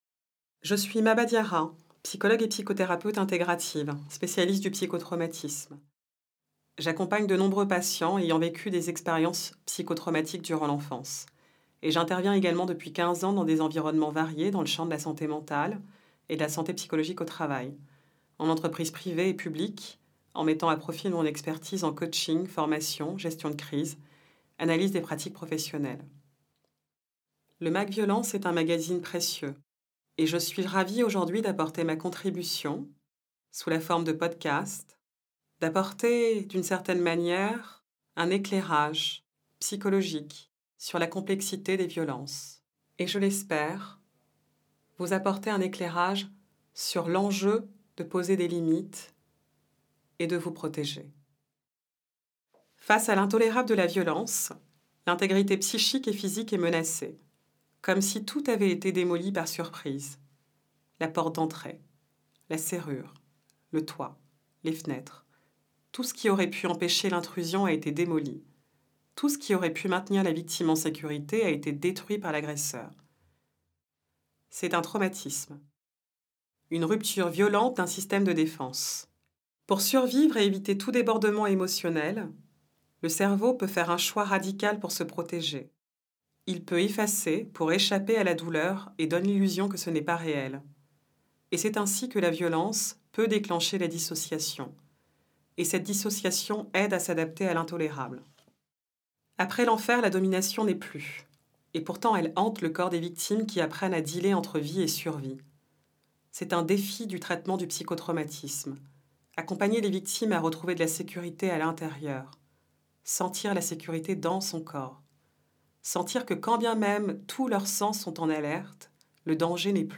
Profitez des articles du Mag’ VIOLENCES en version audio et accédez également aux commentaires des articles par une psychologue, ainsi qu’à des conseils utiles !